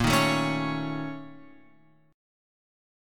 A# Minor Major 7th Double Flat 5th